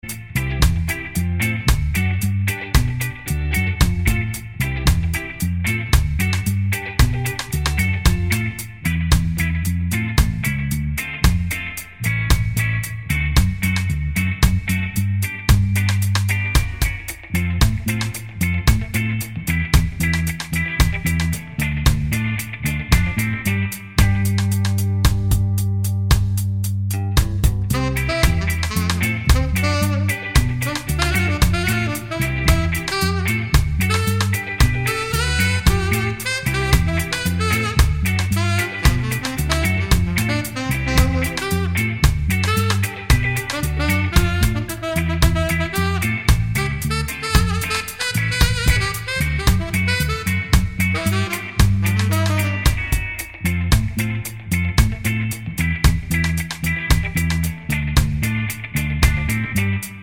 no Backing Vocals Ska 3:02 Buy £1.50